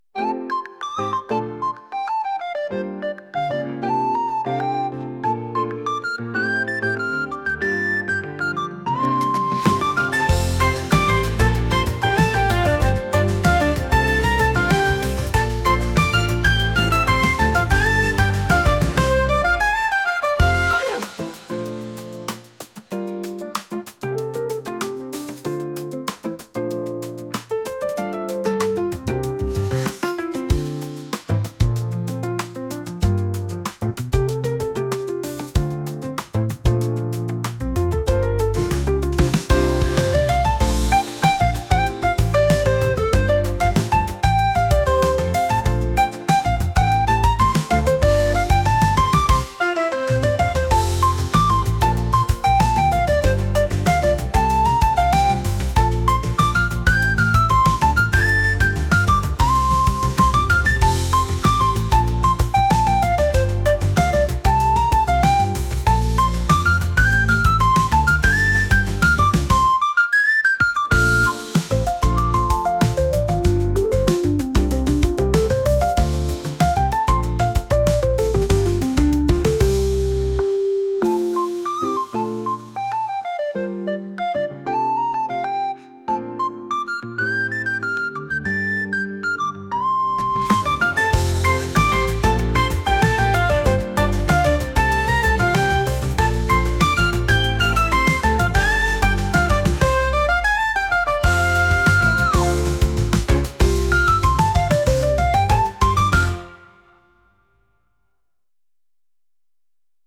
天気のように気分を左右してしまうの君なんだというリコーダー音楽です。